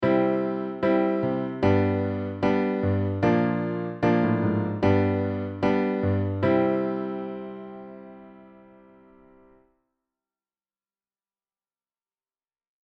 ３番目コードががすっごくふわっとしてるよっ！